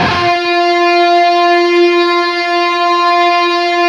LEAD F 3 LP.wav